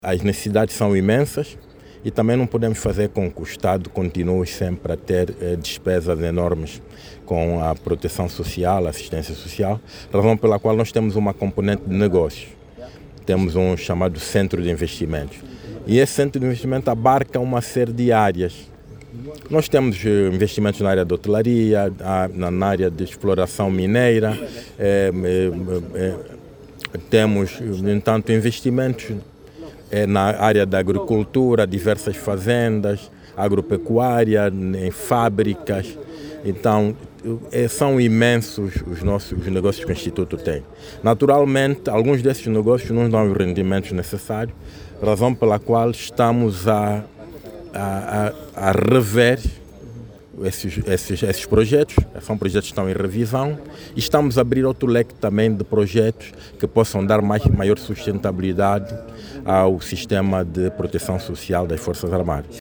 O director geral do Instituto de Segurança Social, Tenente-General Cristóvão Júnior, explica que devido o peso sobre as finanças publicas, a instituição pensa em criar mecanismos para angariar financiamentos para sustentar outras necessidades.